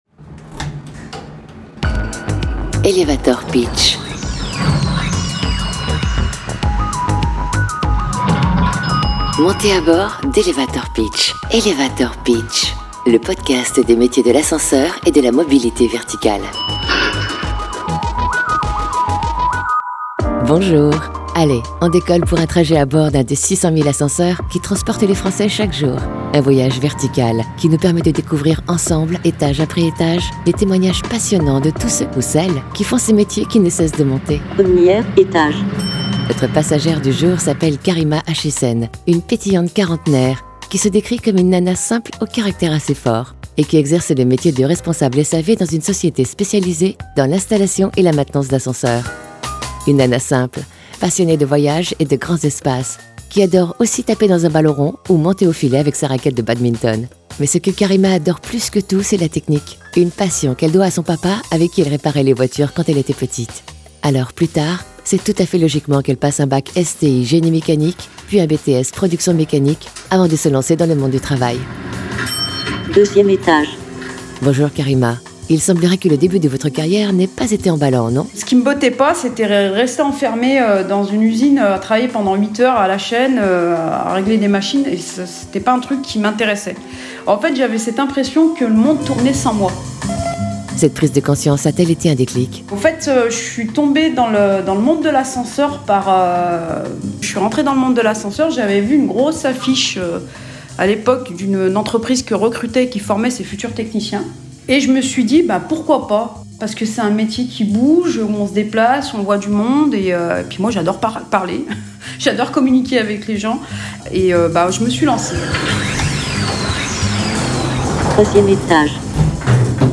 La Fédération des Ascenseurs lance ses podcasts témoignages de professionnels de la mobilité verticale, en valorisant leur parcours formation et/ou professionnel avec une interaction sur l’attachement à leur métier et/ou la réalité de ce dernier.
SAISON 1 : Podcast #4 ASCENSEUR ET COULISSES, avec une responsable SAV